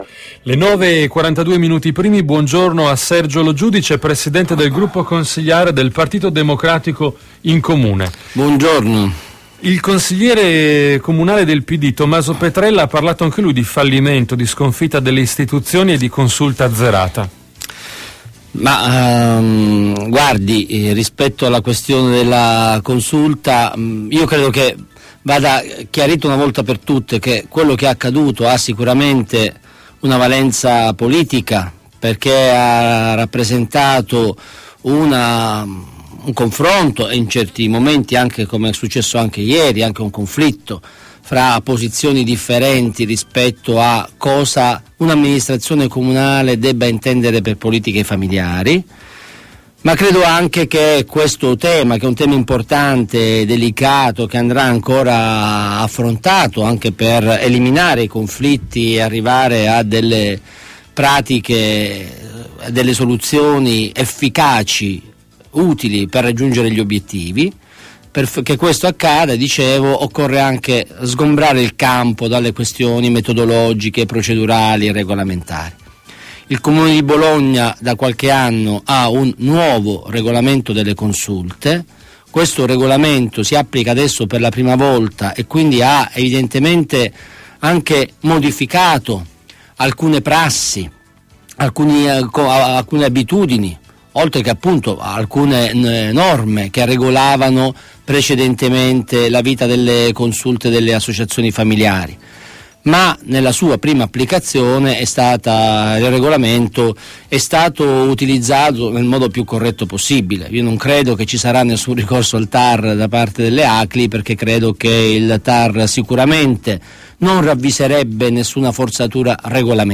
Intervista a Radio Tau del capogruppo PD Sergio Lo Giudice il 7 dicembre